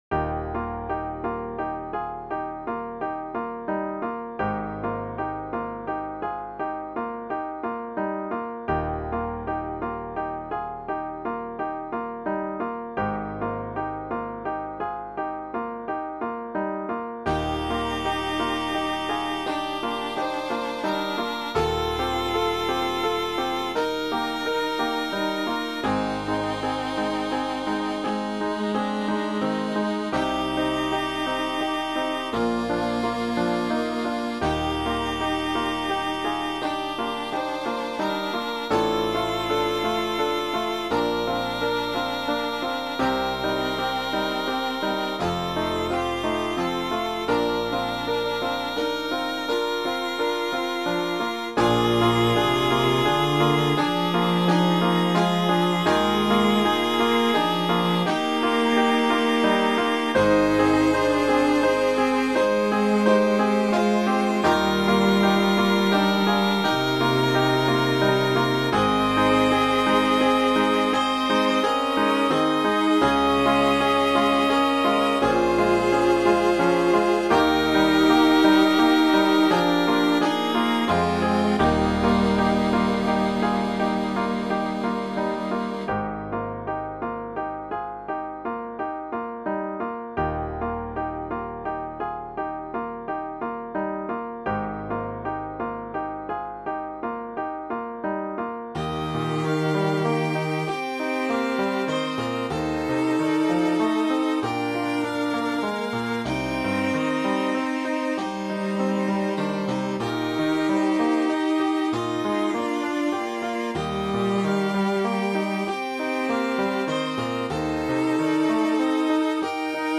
This favorite hymn has been arranged as a string trio. It starts out slow and relaxed and speeds up a bit on verses 2 and 3. Each instrument gets to have the melody. The piano accompaniment is flowing and soothing.
String Trio